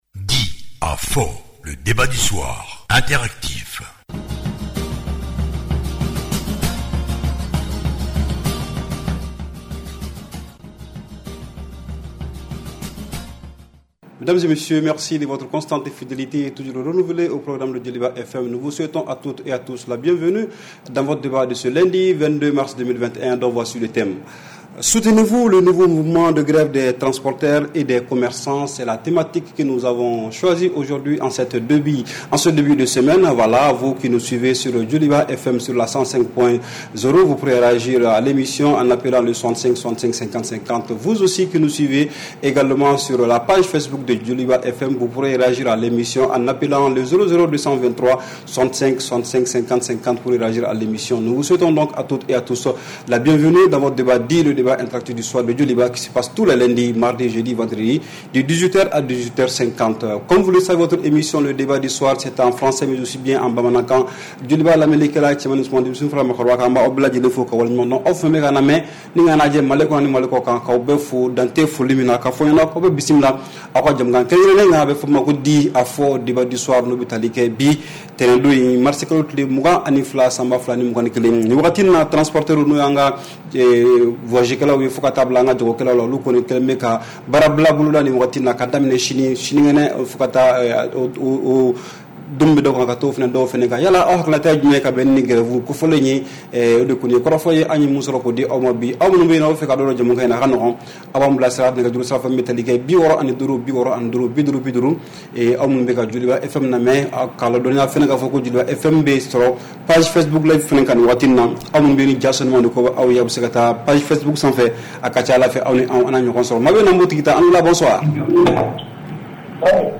REPLAY 22/03 – « DIS ! » Le Débat Interactif du Soir